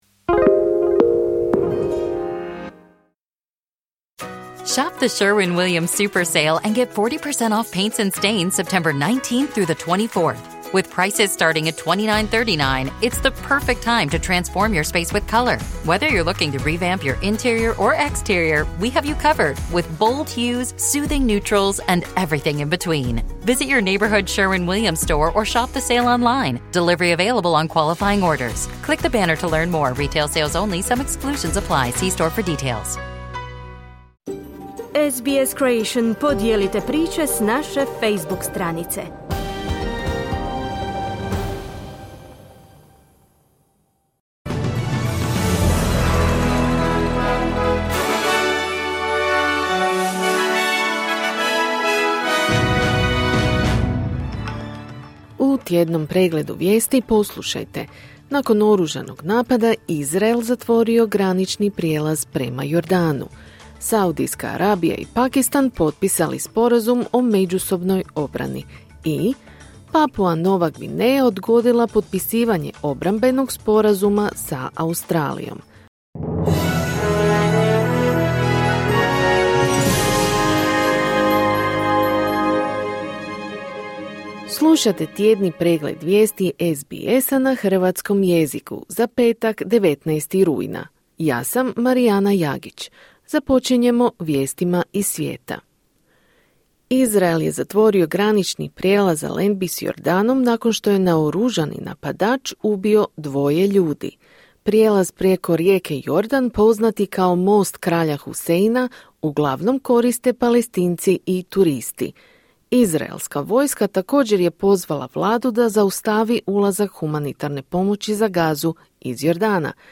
Tjedni pregled vijesti, 19.9.2025.
Vijesti radija SBS.